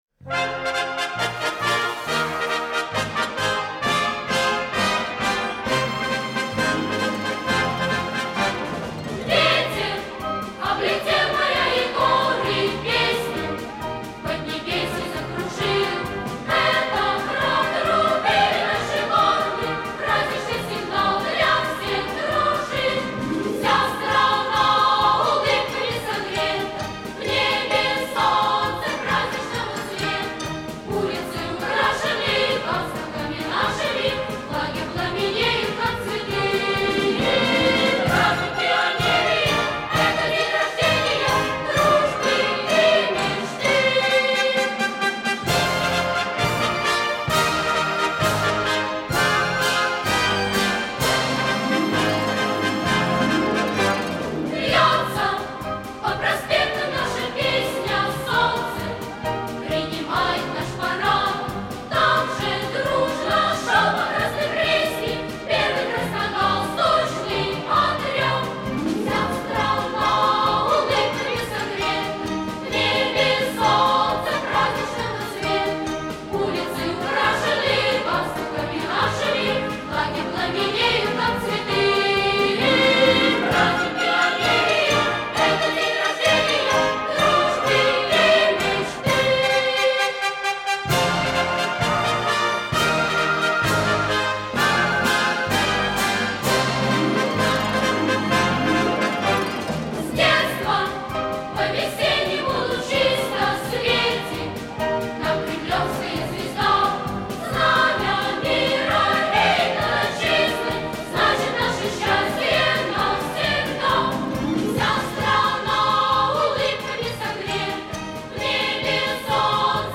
Пионерские песни